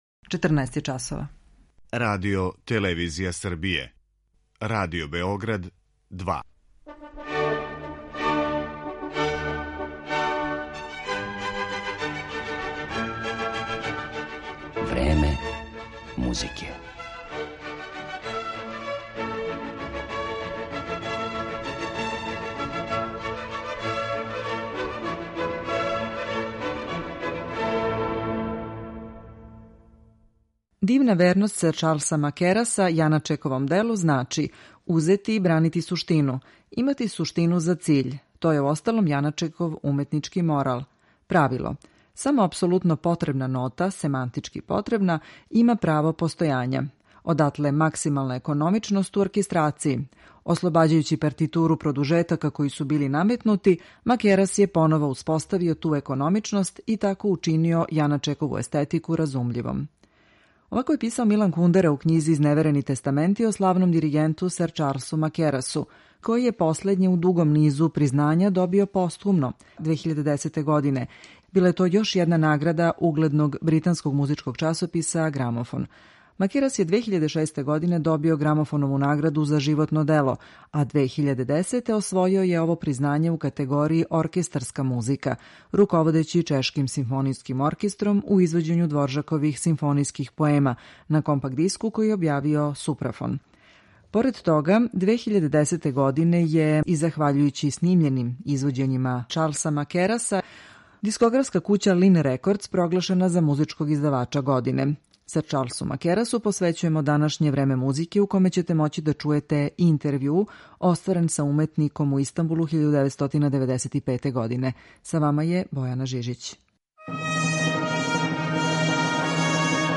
У извођењу композиција чешких аутора, као и Моцарта и Брамса, представићемо га у данашњем Времену музике , у коме ће бити емитован и интервју снимљен са овим славним уметником 1995. године у Прагу.